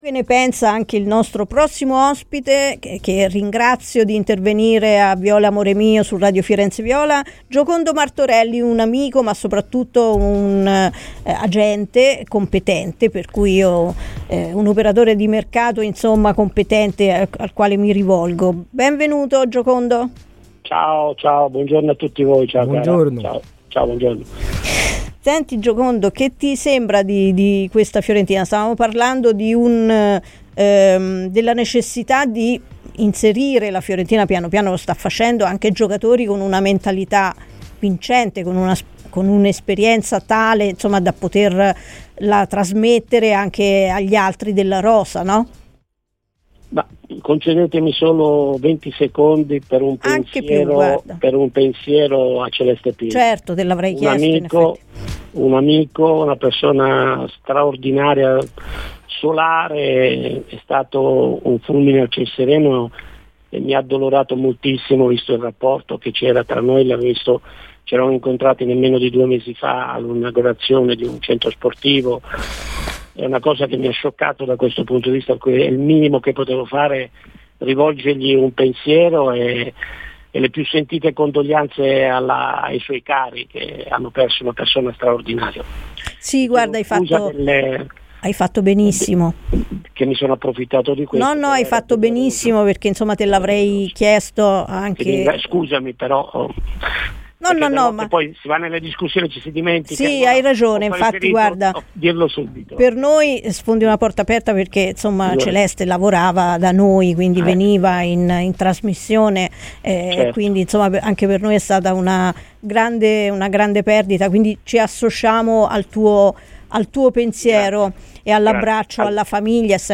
ha parlato così ai microfoni di Radio FirenzeViola , durante la trasmissione "Viola Amore Mio"